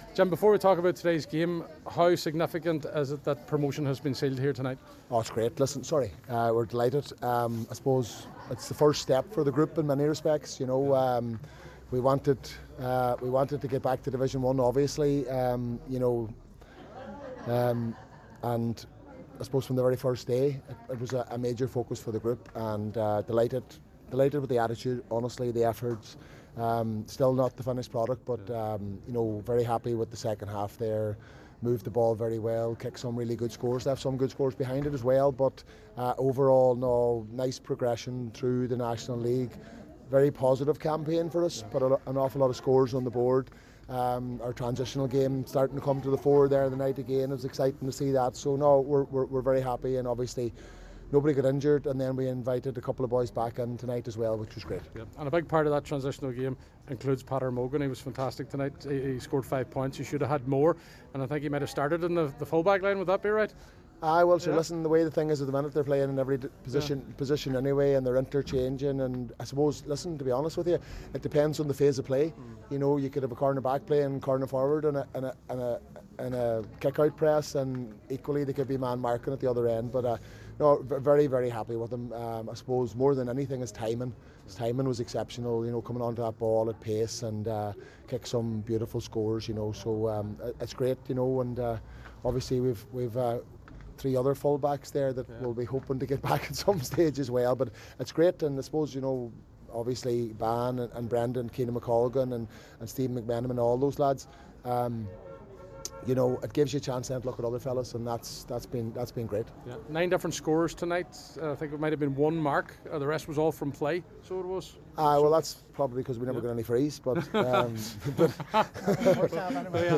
The Glenties man gave his thoughts